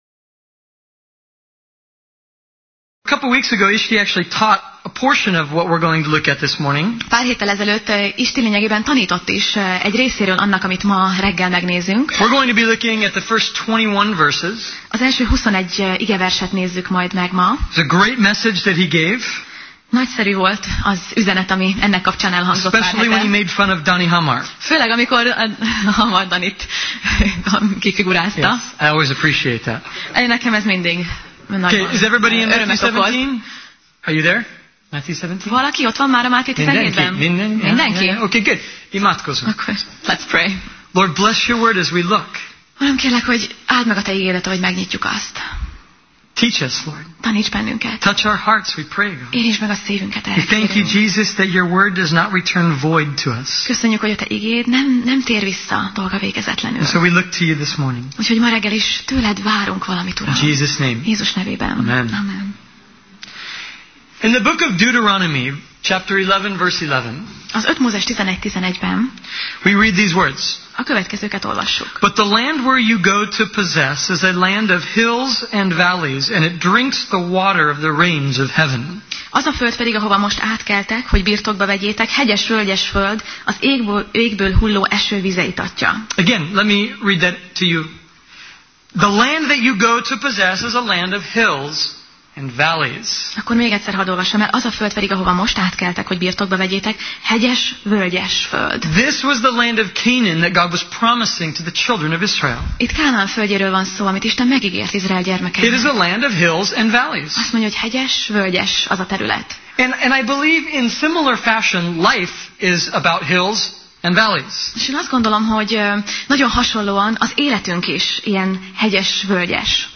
Máté Passage: Máté (Matthew) 17:1–21 Alkalom: Vasárnap Reggel